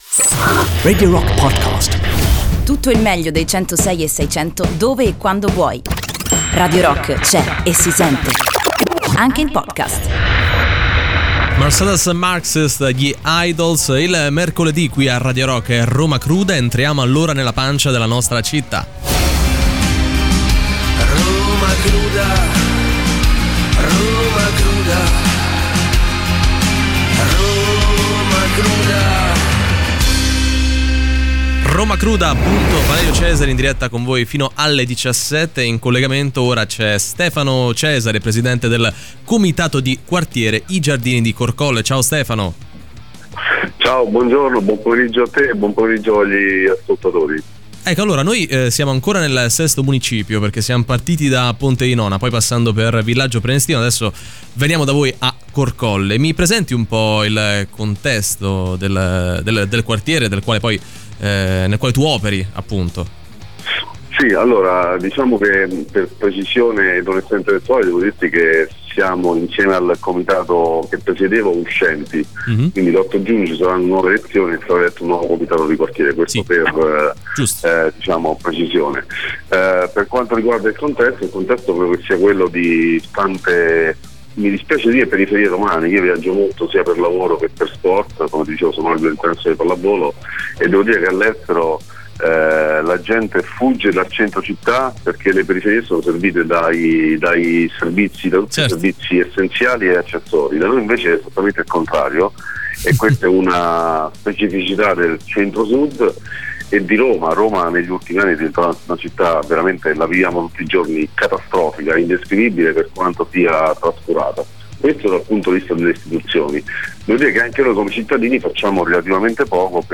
In diretta sui 106.6 di Radio Rock ogni mercoledì a partire dalle 15:30.